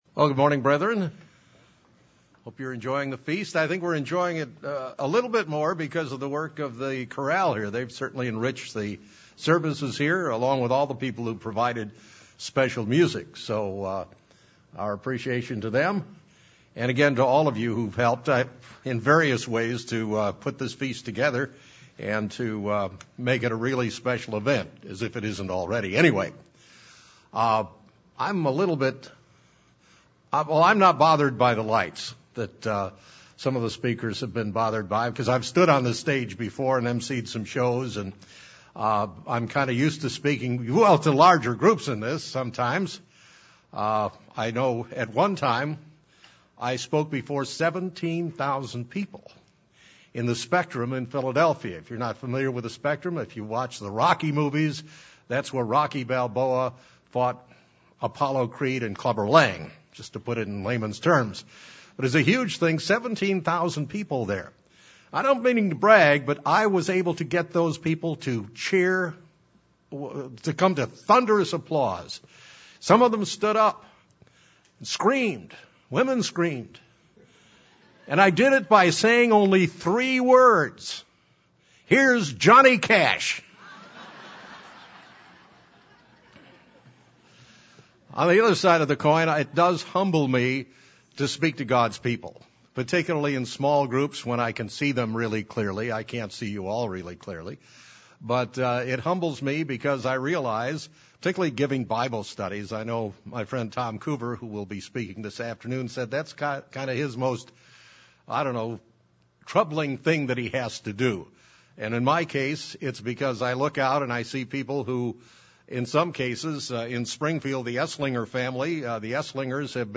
This sermon was given at the Branson, Missouri 2014 Feast site.